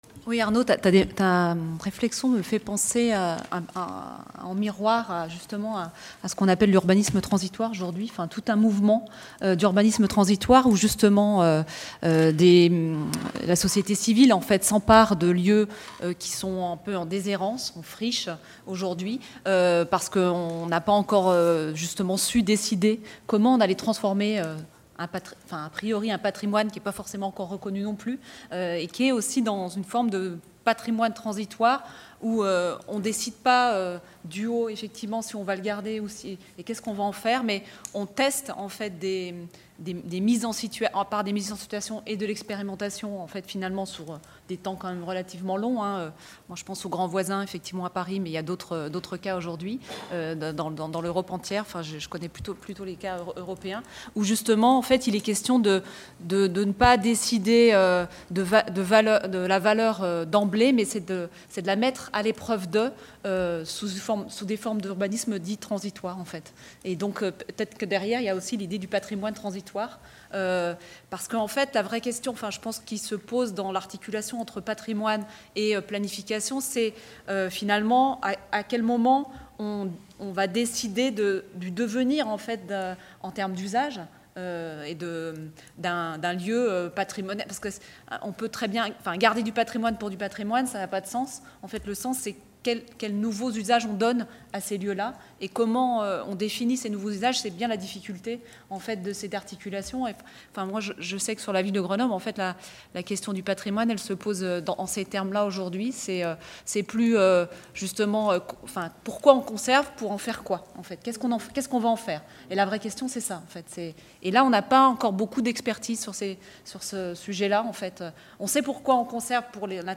11. Synthèse comparative : table-ronde de conclusions (partie 2) | Canal U